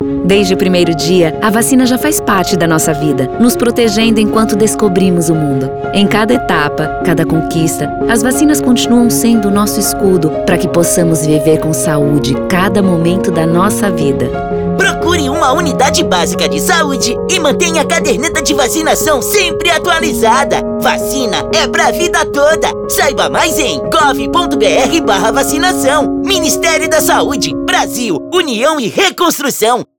Áudio - Spot 30seg - Vacinação de Rotina - 1,15mb .mp3 — Ministério da Saúde